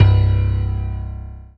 PIANO5-01.wav